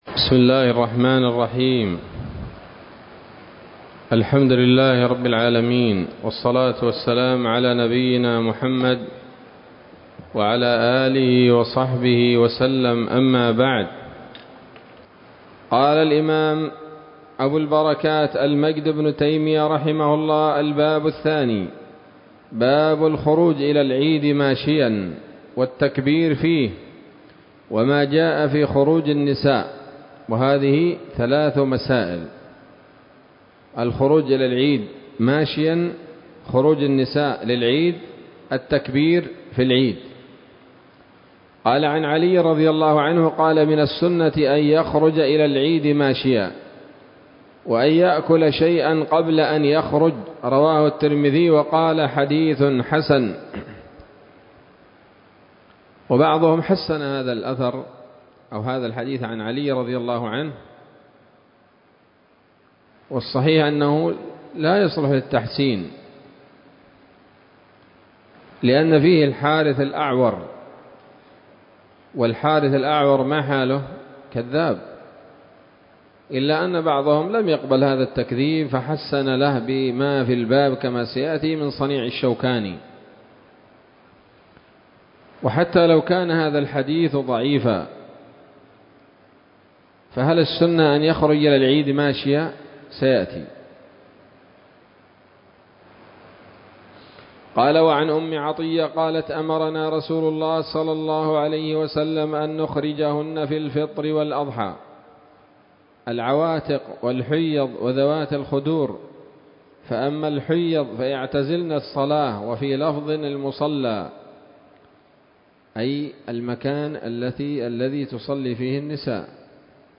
الدرس الثاني من ‌‌‌‌كتاب العيدين من نيل الأوطار